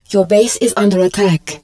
marine_baseattack2.wav